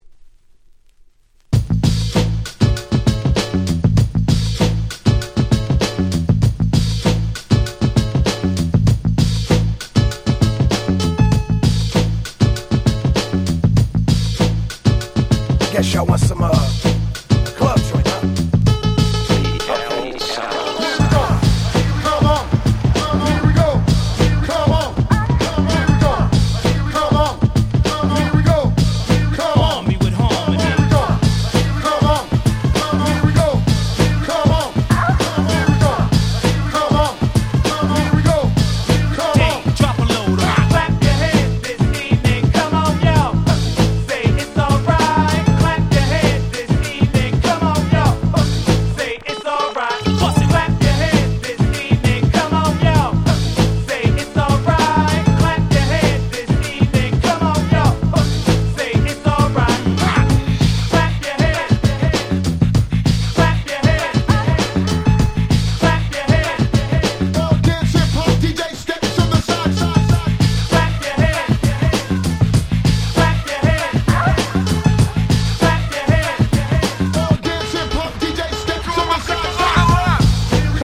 07' Nice Party Tracks / Mash Up !!
00's Hip Hop R&B